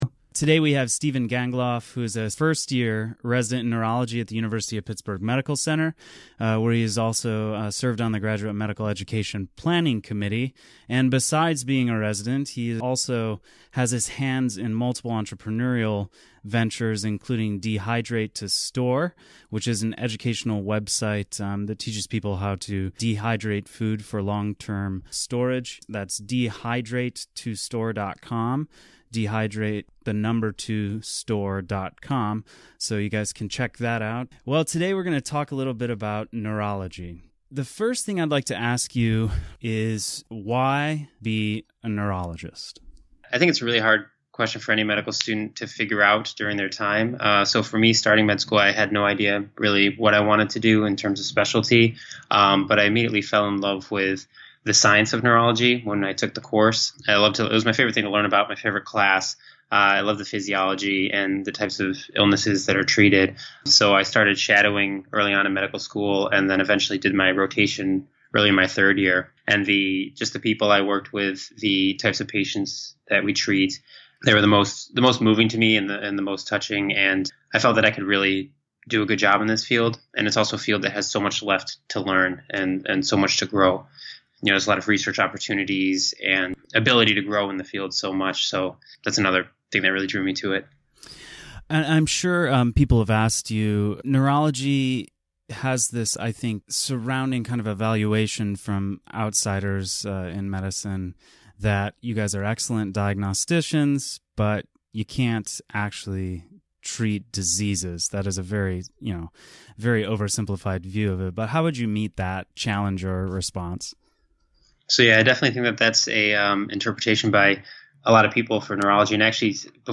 INSIDETHEBOARDS Interview